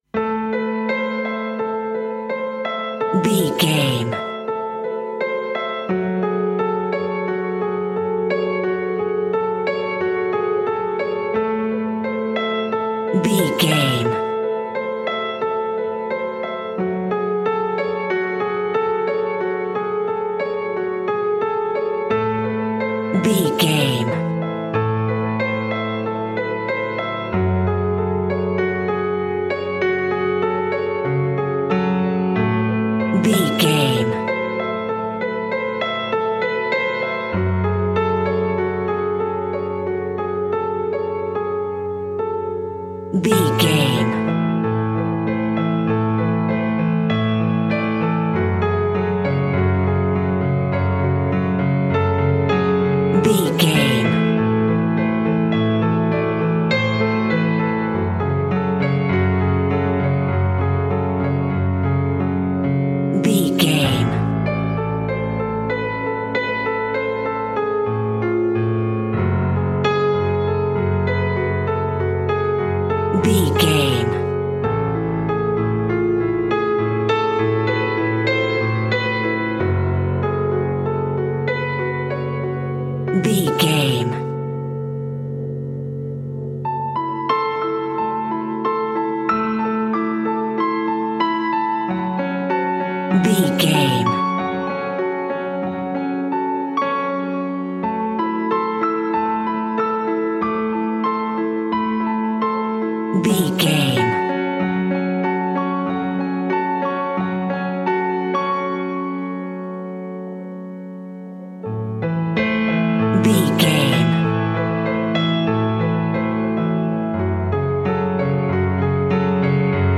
In-crescendo
Thriller
Aeolian/Minor
scary
ominous
dark
suspense
eerie
horror
synth